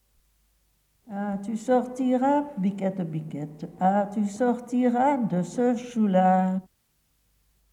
Type : chanson d'enfants | Date : 1972/73
Mode d'expression : chant Lieu